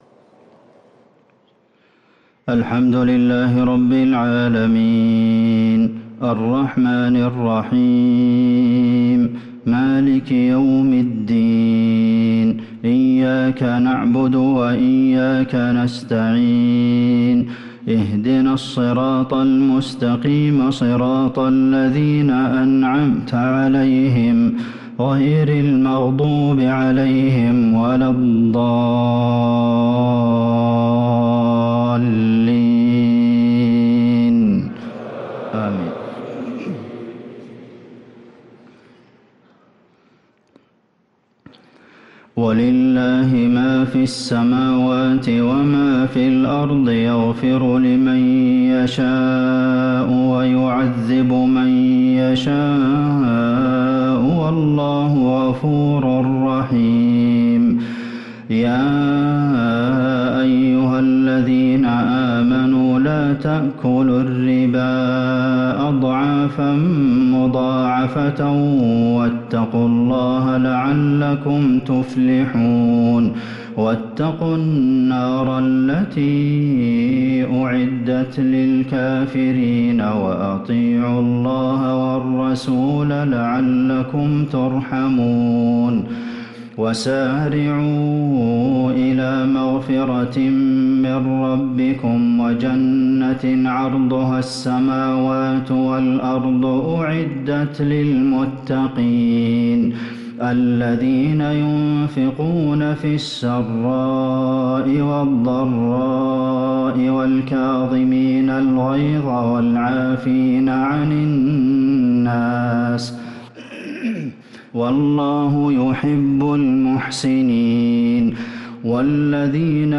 صلاة العشاء للقارئ عبدالمحسن القاسم 23 ذو القعدة 1444 هـ
تِلَاوَات الْحَرَمَيْن .